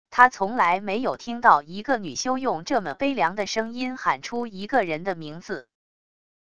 他从来没有听到一个女修用这么悲凉的声音喊出一个人的名字wav音频生成系统WAV Audio Player